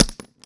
grenade_hit_carpet_02.WAV